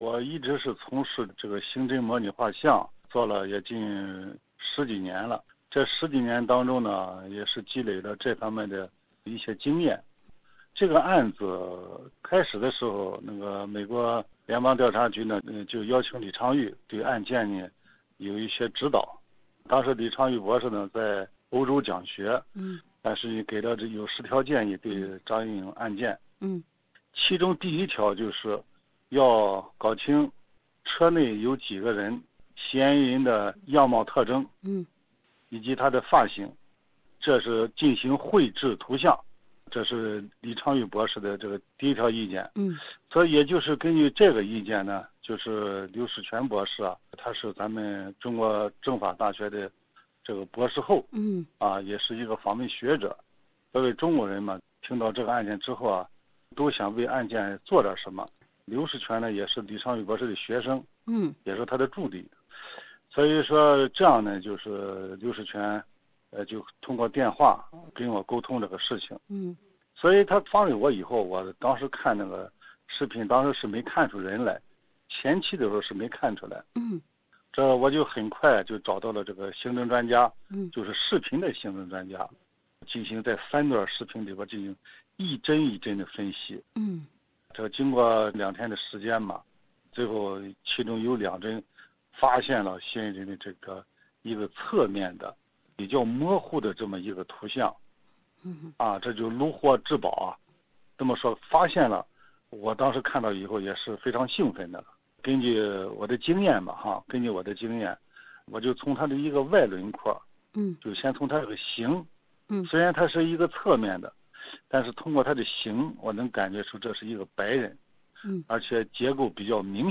专访